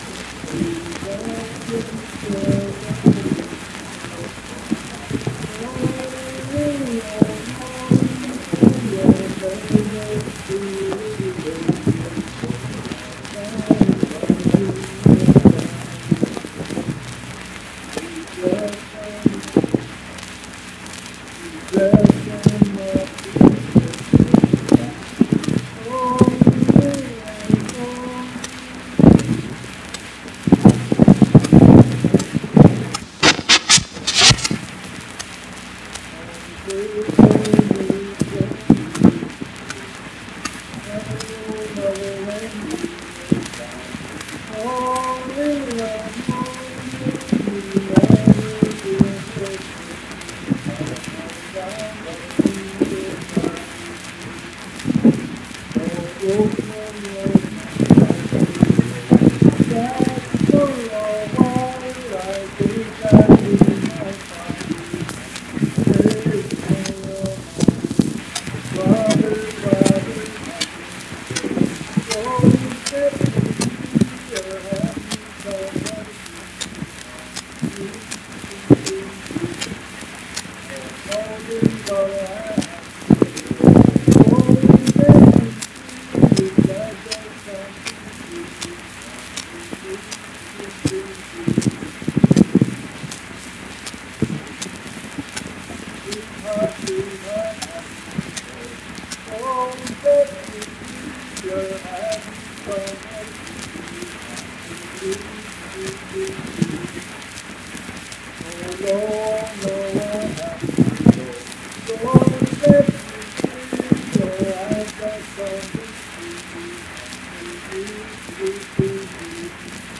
Unaccompanied vocal music performance
Voice (sung)
Randolph County (W. Va.)